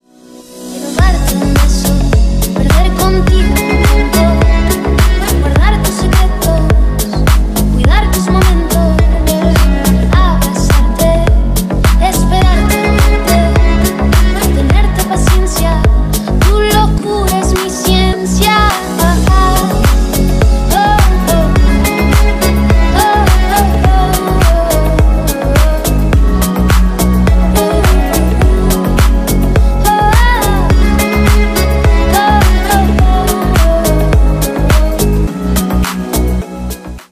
Ремикс
клубные # латинские